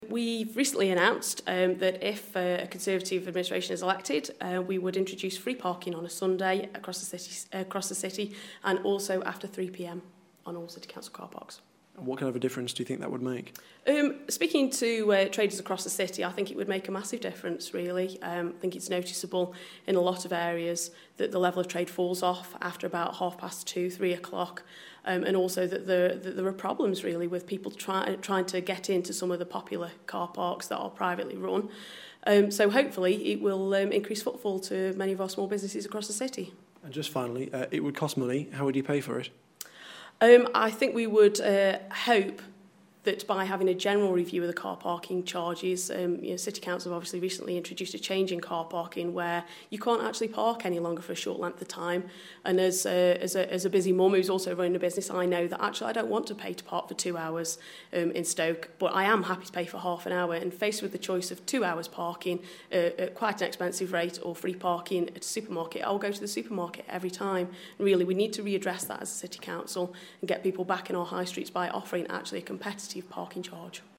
The Tories say they'll introduce free parking after 3pm every day and all day on Sundays if they win control of Stoke-on-Trent city Council at next year's elections. I spoke to group leader Abi Brown.